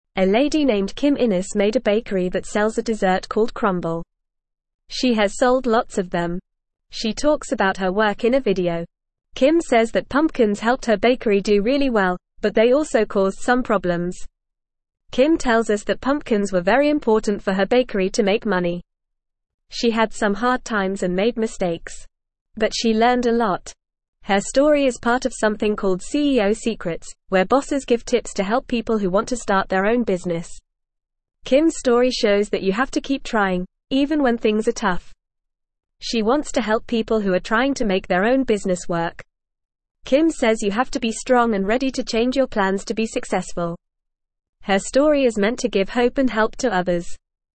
Fast